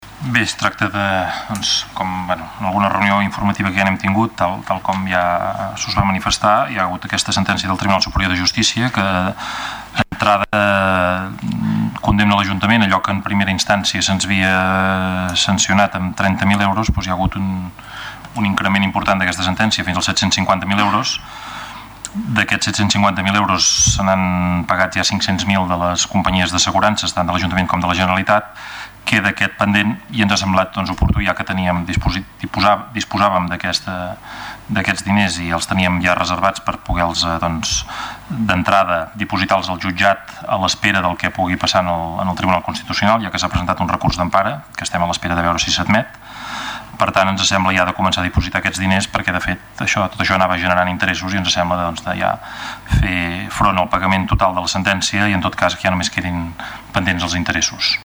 Lluís Verdaguer en el ple de dijous, 500.000 els han pagat les companyies d'assegurances tant de l'Ajuntament com de la Generalitat.